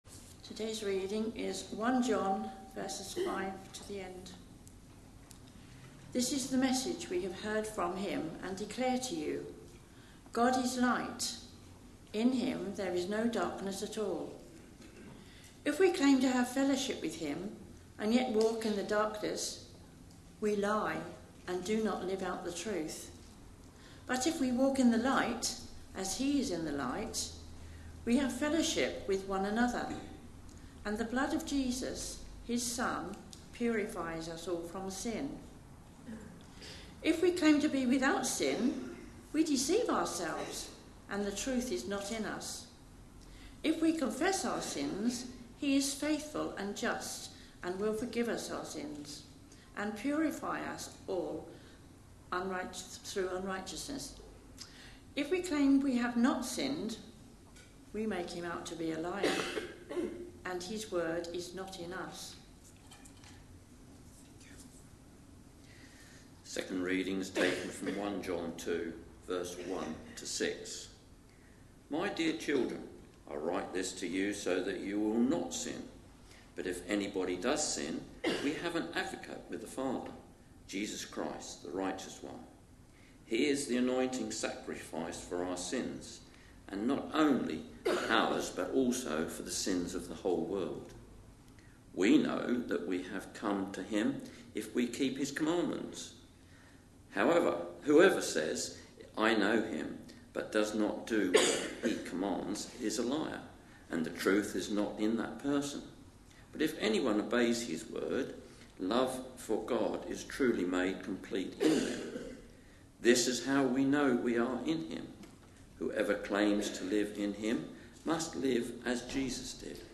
A sermon preached on 30th September, 2018, as part of our Basic Christianity. series.